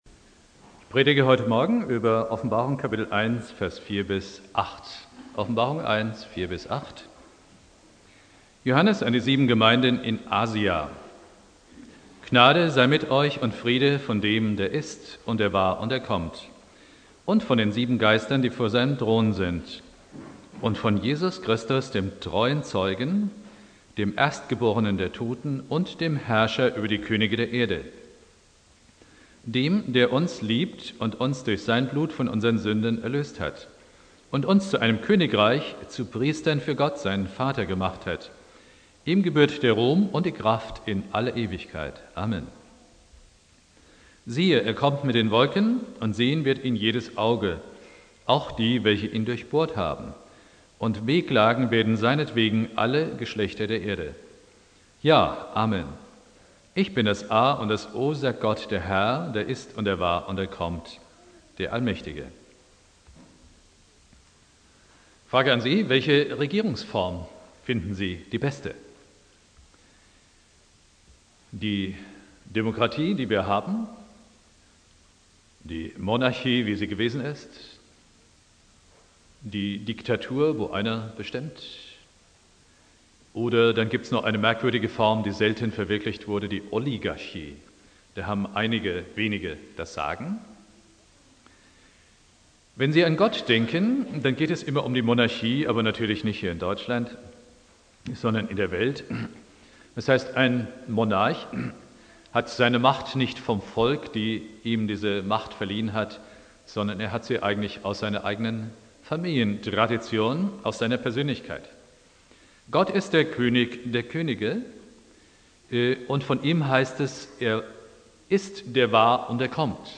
Predigt
Christi Himmelfahrt